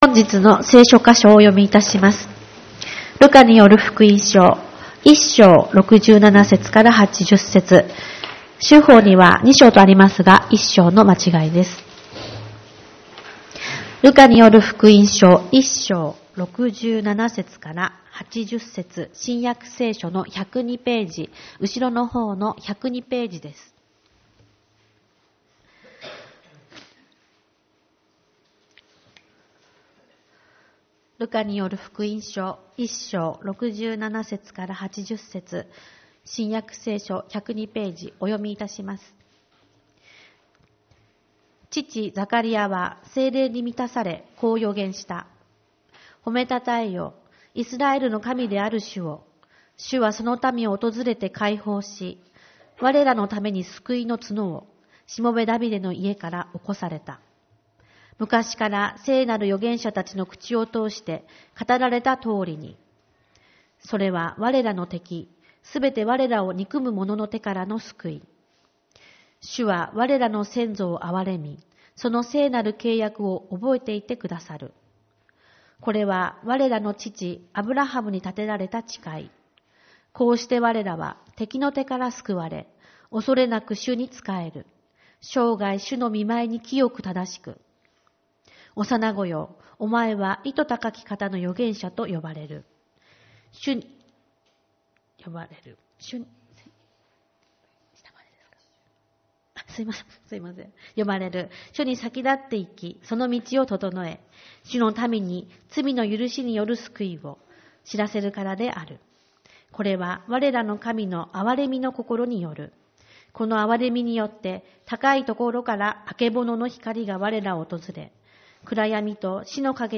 主日礼拝 「愛と赦しのクリスマス」 ルカによる福音書2:67-80